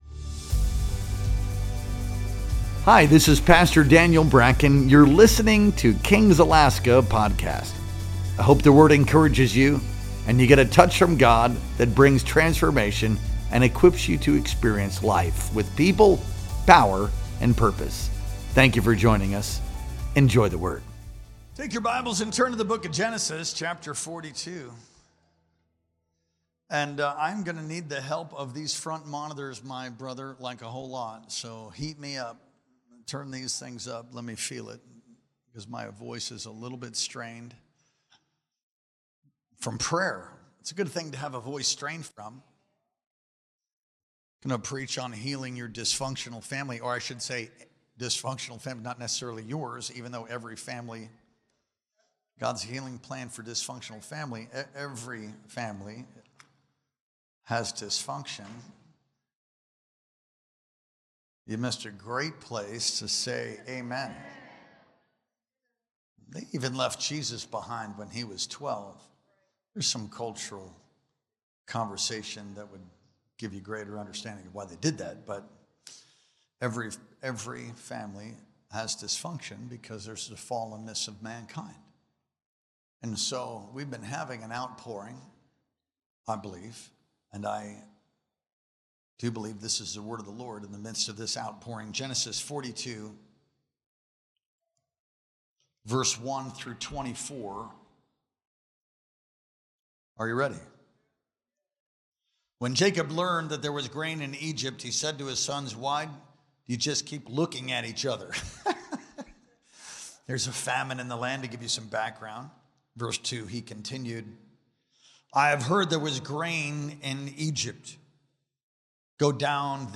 Our Wednesday Night Worship Experience streamed live on March 26th, 2025.